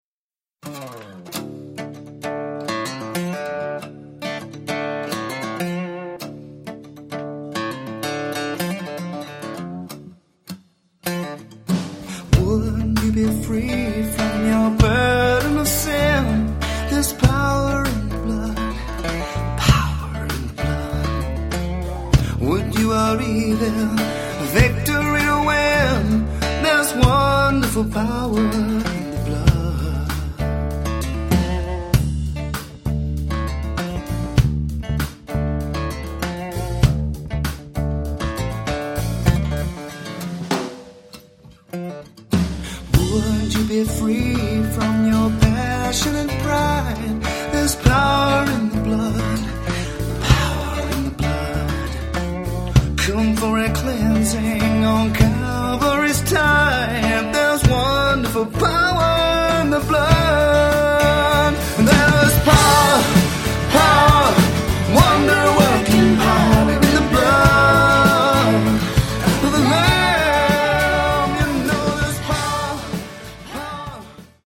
beloved hymn—an Americana-Blues style that audiences love.